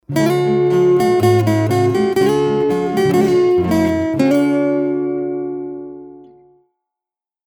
гитарист